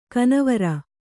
♪ kanavara